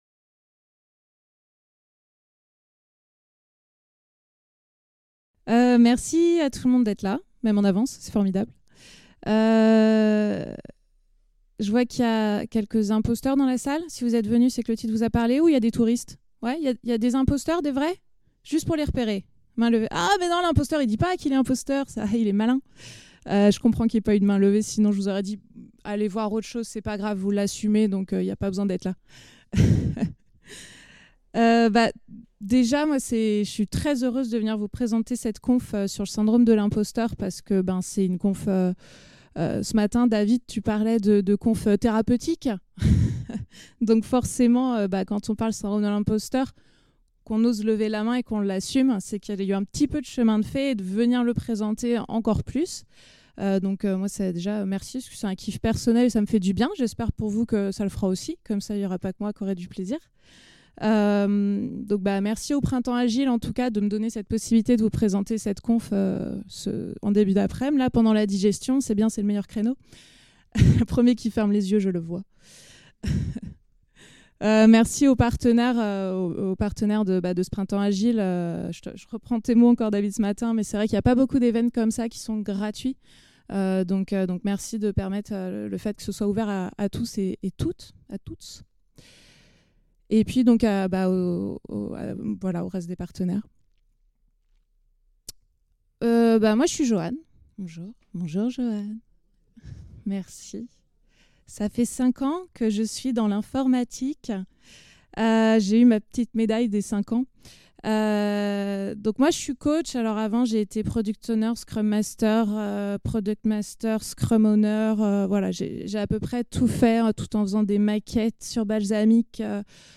Le printemps agile 2024 fait son festival